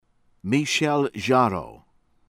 HUWART, FRANCOIS frah(n)-SWAH    oo-WAHRT